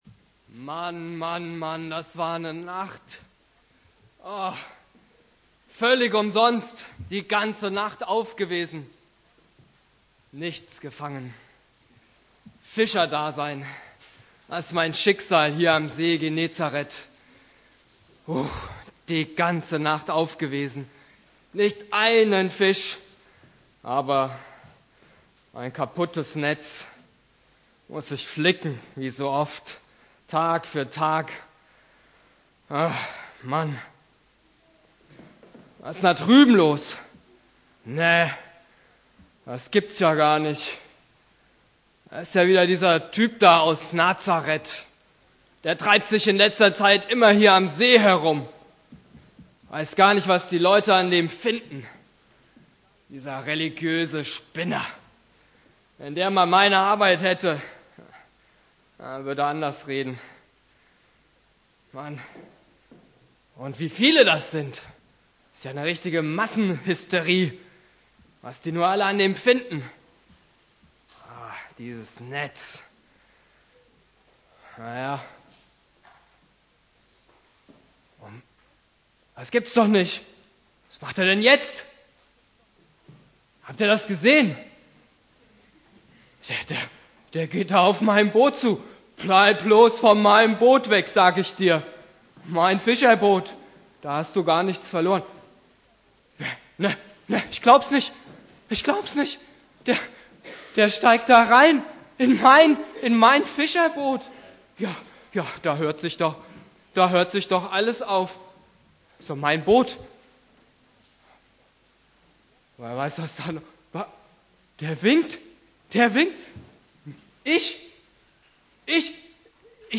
Predigt
Thema: Fischfang Petrus (Konfirmation Hausen